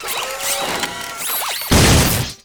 robot punch.wav